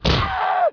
nobleman_die.wav